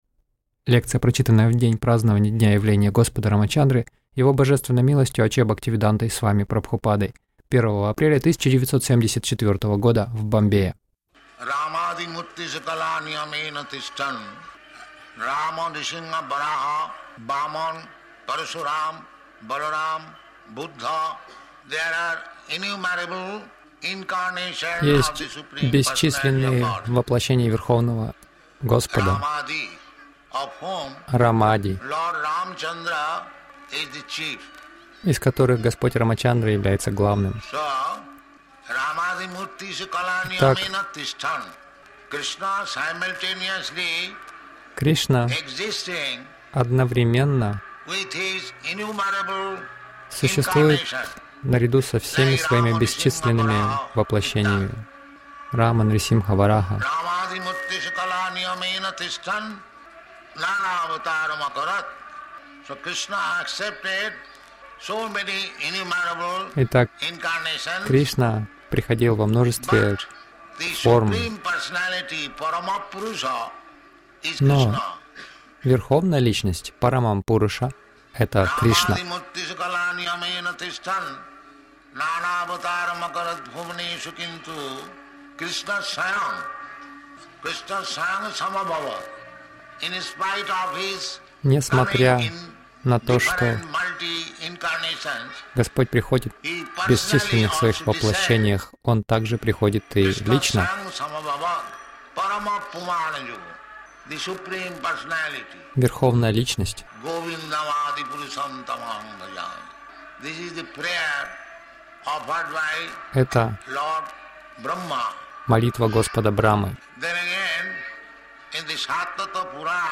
Лекция по Брахма-самхите — О Господе Раме (Раманавами)
Милость Прабхупады Аудиолекции и книги 01.04.1974 Праздники | Бомбей Лекция по Брахма-самхите — О Господе Раме (Раманавами) Загрузка...